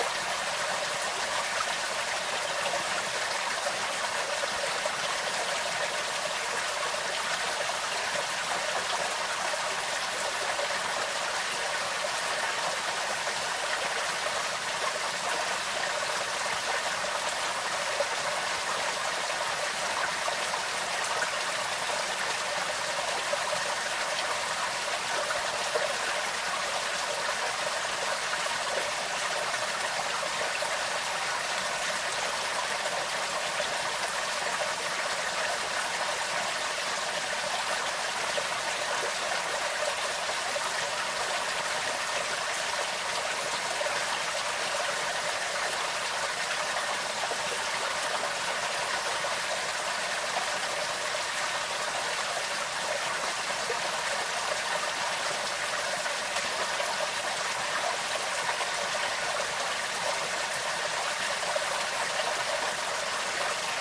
现场记录。水 " 岩石下的瀑布
描述：使用内置麦克风的Zoom H1录制的Wav文件。一条小河在一些石头下面流入森林中的一个盆地。在瑞士提契诺（Tessin）。
Tag: 丘陵 河流 fieldrecording 氛围 环境 森林 瀑布 瑞士 岩石 氛围 溶洞 自然 fieldrecording 提契诺州 提契诺